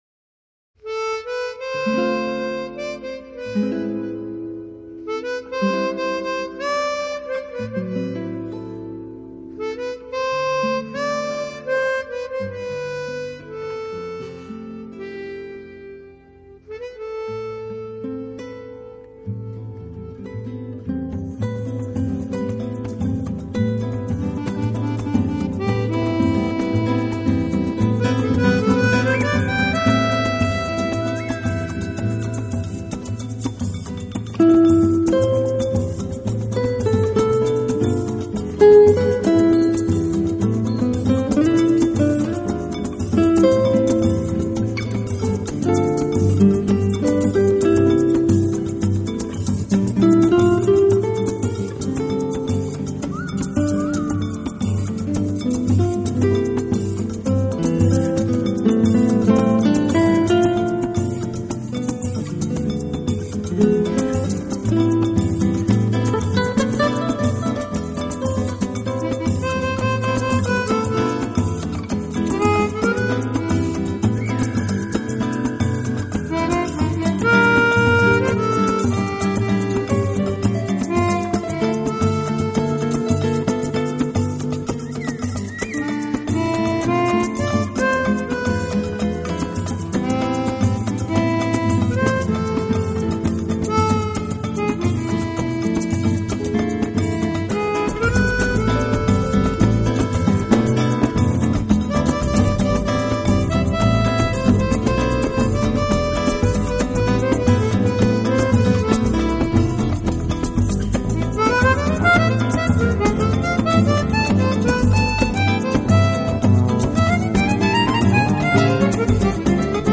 Genre：Jazz